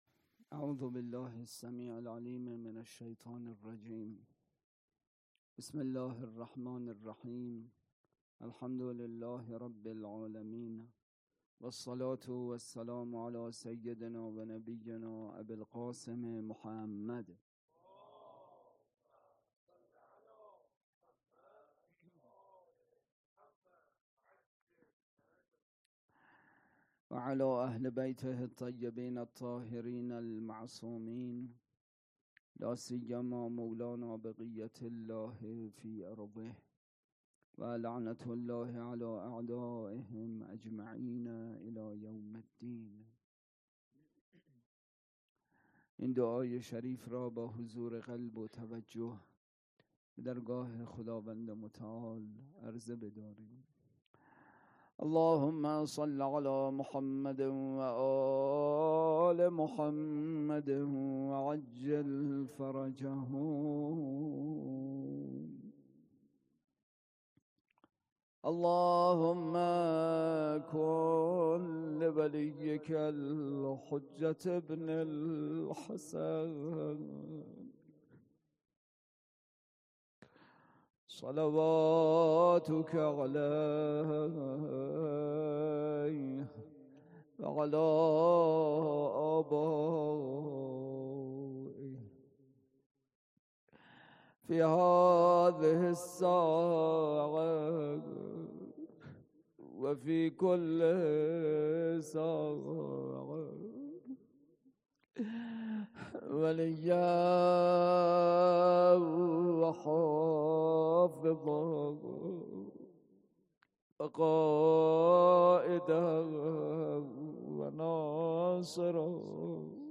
اطلاعات آلبوم سخنرانی